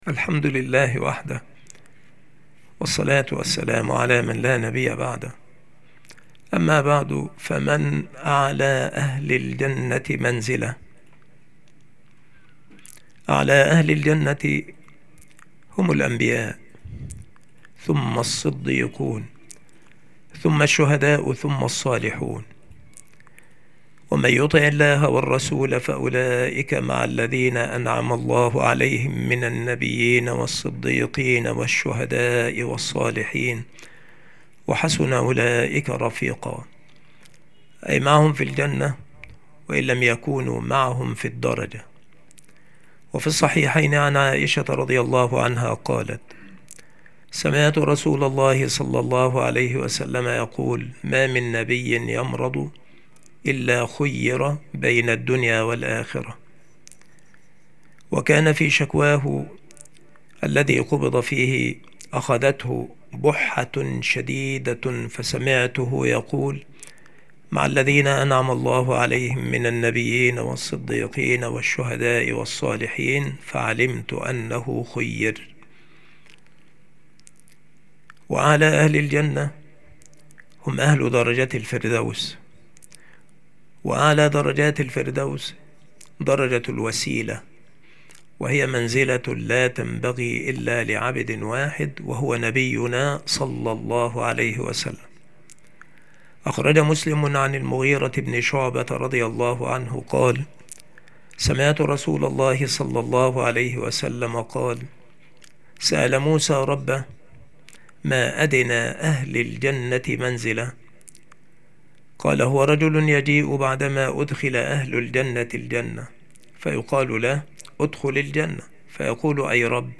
المحاضرة
مكان إلقاء هذه المحاضرة المكتبة - سبك الأحد - أشمون - محافظة المنوفية - مصر